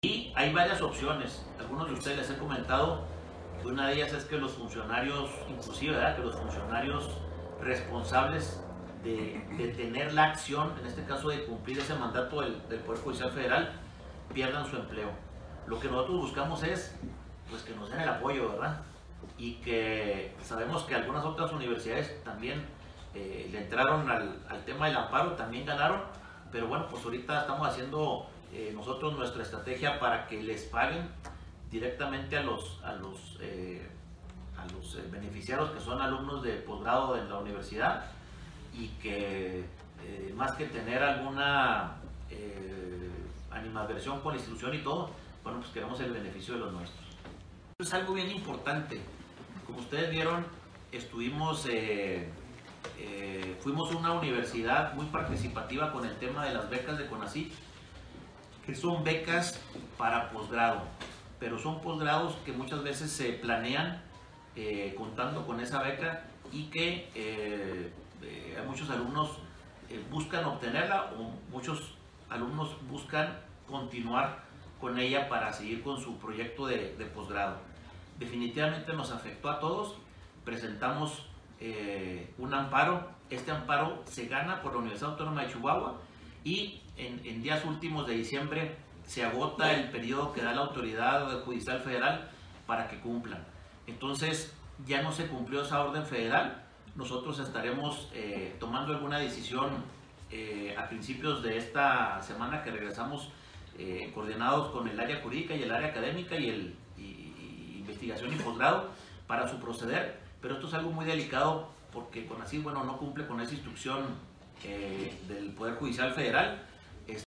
AUDIO: LUIS RIVERA CAMPOS, RECTOR DE LA UNIVERSIDAD AUTÓNOMA DE CHIHUAHUA (UACh)